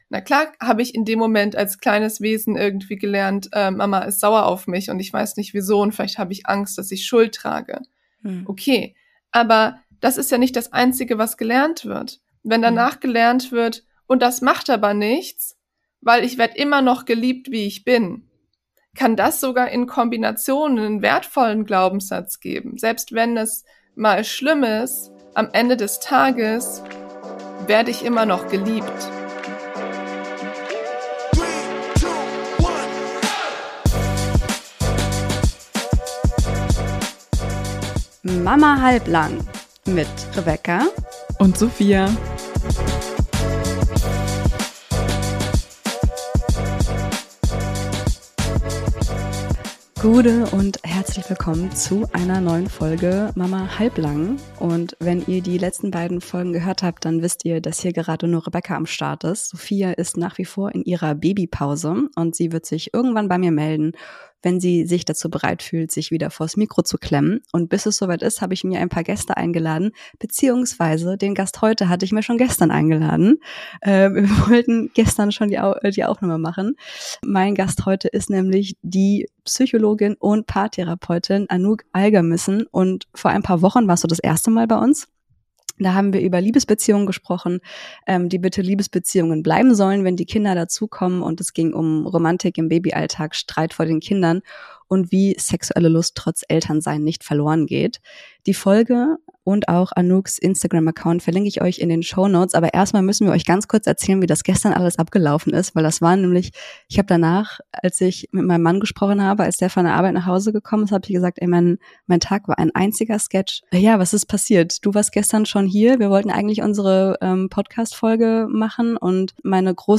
SPECIAL: Mama schreit – Interview mit Psychologin über Wut, Trauer und Frust im Eltern-Alltag ~ MAMA HALBLANG! Podcast